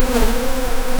- Einführung von Soundeffekten für Bewegungen, Bomben, Raketen und Regenbogen-Animationen zur Steigerung des Spielerlebnisses.